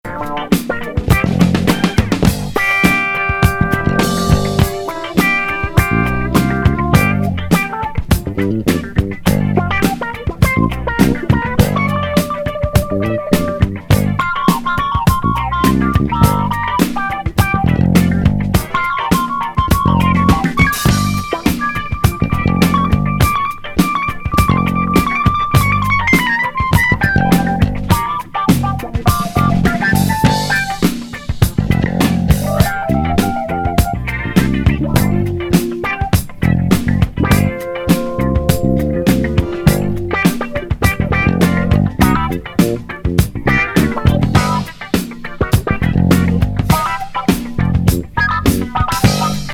ファンキー・フュージョン!